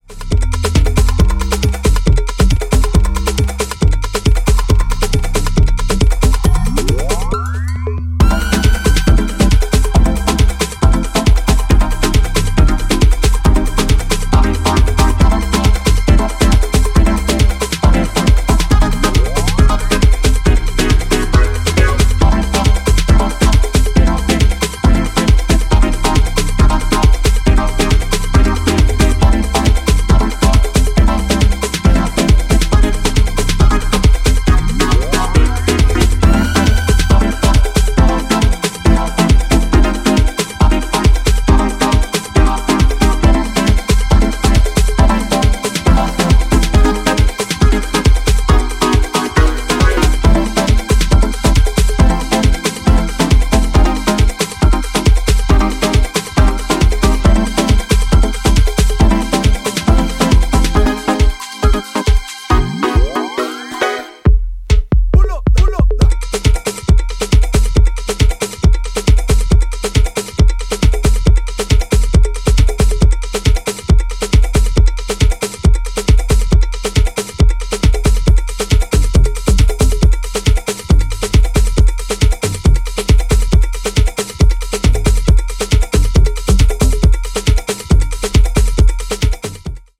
極太の鳴りがフロアを突き抜けるアナログ・マシン駆動のソカ・チューン